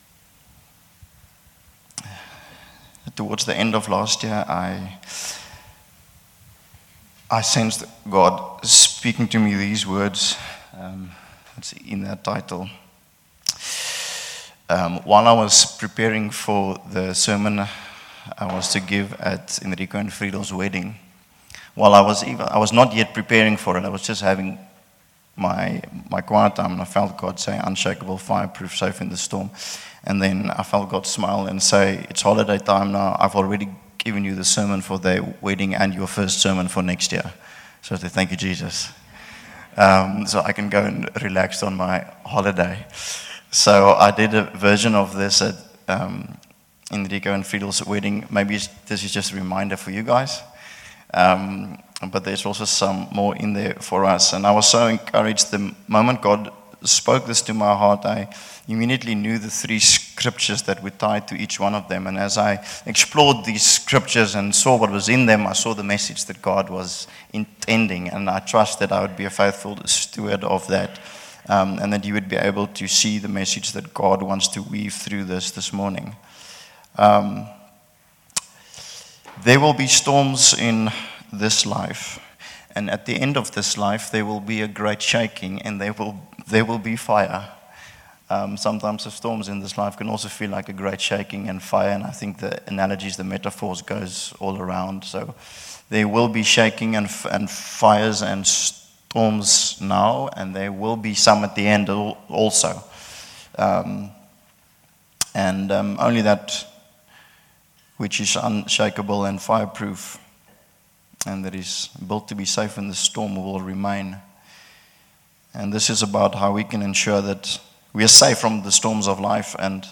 Sermons by Shofar Cape Town City